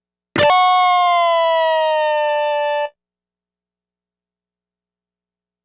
Swoop Down.wav